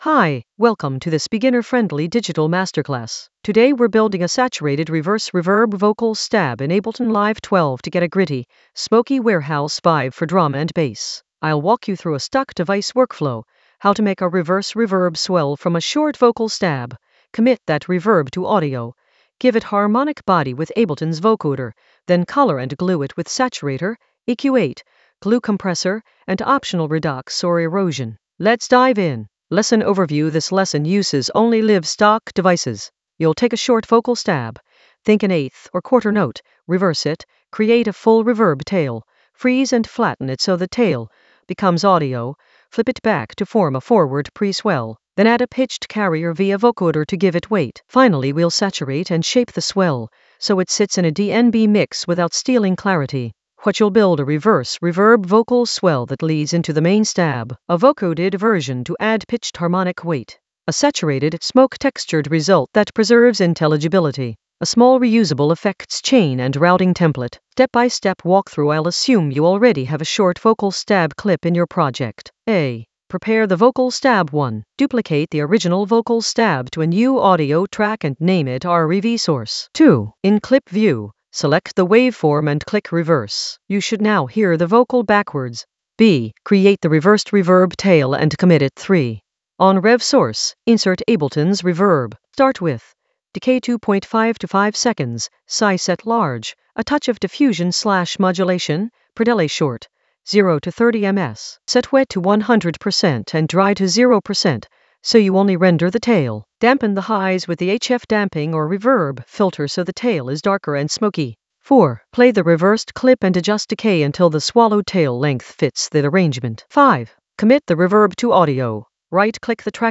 An AI-generated beginner Ableton lesson focused on Digital masterclass: saturate the reverse reverb stab in Ableton Live 12 for smoky warehouse vibes in the Vocals area of drum and bass production.
Narrated lesson audio
The voice track includes the tutorial plus extra teacher commentary.